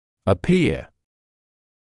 [ə’pɪə][э’пиэ]казаться; выглядеть; появляться